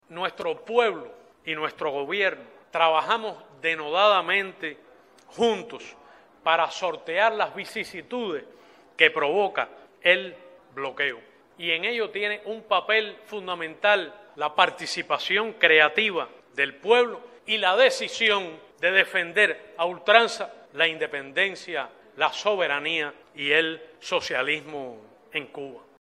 Así lo denunció en conferencia de prensa el ministro de Relaciones Exteriores Bruno Rodríguez Parrilla al presentar, tanto al cuerpo diplomático como a los medios de comunicación nacionales y foráneos, el informe Necesidad de poner fin al bloqueo económico, comercial y financiero impuesto por los Estados Unidos de América contra Cuba.